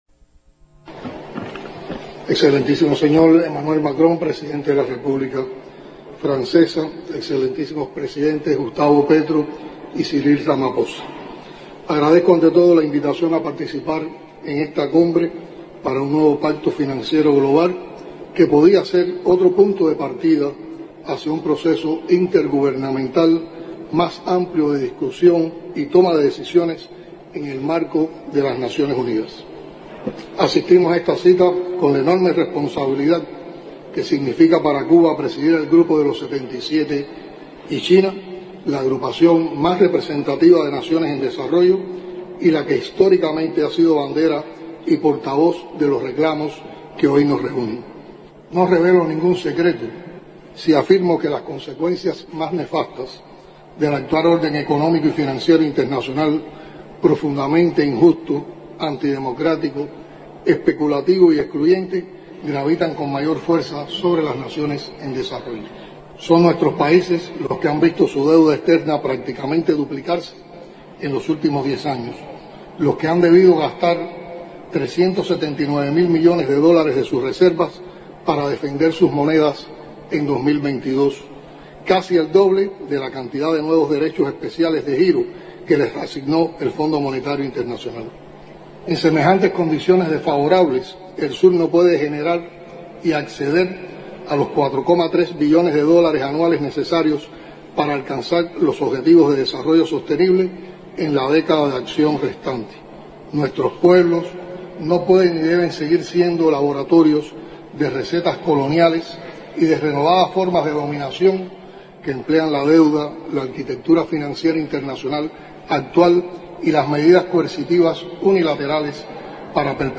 Discursos
palabras-de-diaz-canel-en-la-cumbre-para-un-nuevo-pacto-financiero-mundial-en-paris.mp3